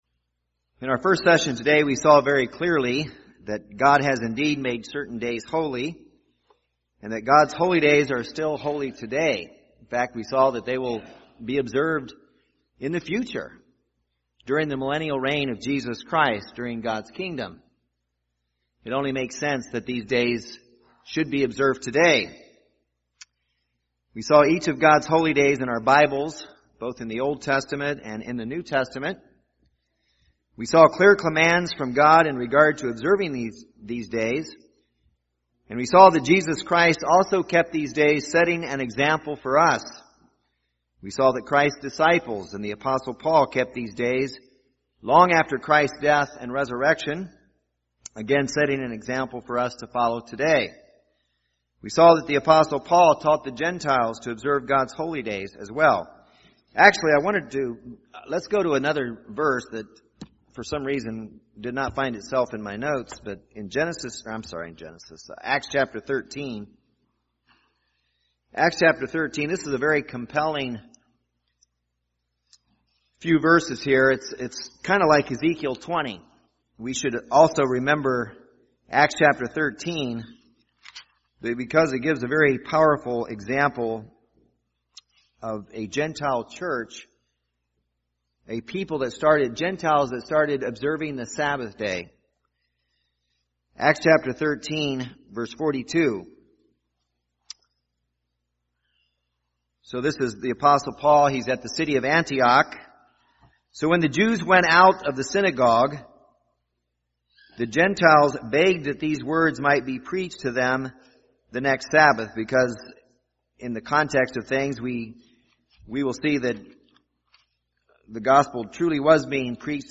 Kingdom of God Bible Seminar Series, Part 6, Session 2 Why do we observe God’s Holy Days today?